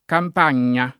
kamp#n’n’a] s. f. — sim. il top. Campagna e i cogn. Campagna, Campagni — come top., anche usato dai toscani del Medioevo come equivalente a volte di Campania, a volte di Champagne